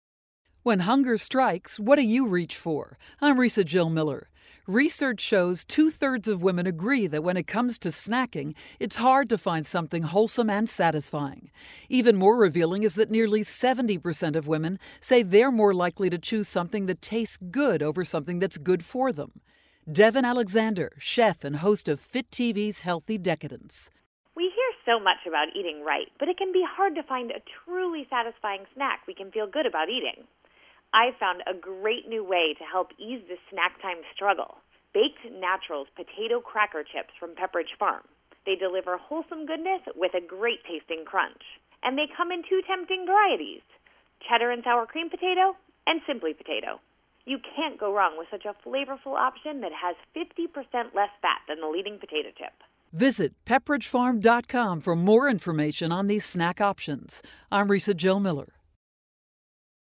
May 1, 2012Posted in: Audio News Release